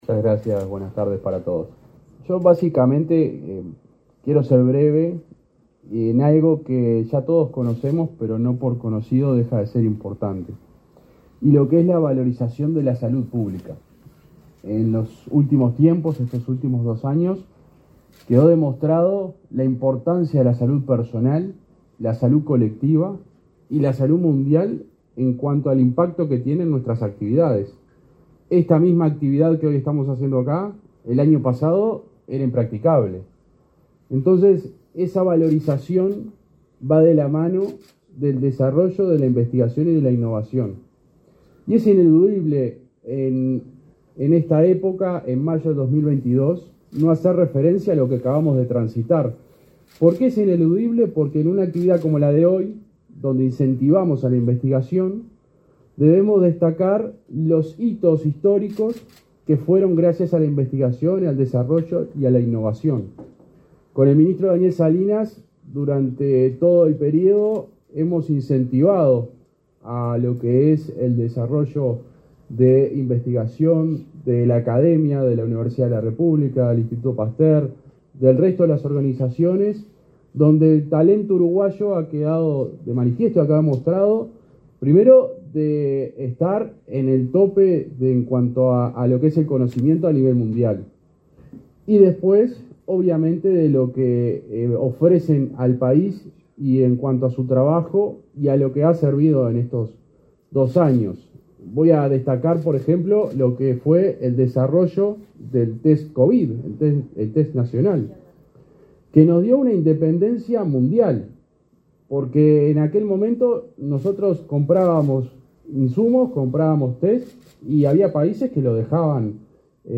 Conferencia de prensa por el lanzamiento del Fondo de Salud en Investigación Clínica
Conferencia de prensa por el lanzamiento del Fondo de Salud en Investigación Clínica 26/05/2022 Compartir Facebook X Copiar enlace WhatsApp LinkedIn Este 26 de mayo se realizó el lanzamiento del Fondo de Salud en Investigación Clínica, con la participación del subsecretario de Salud Pública, José Luis Satdjian, y el director de la Agencia Nacional de Investigación e Innovación, Pablo Caputi.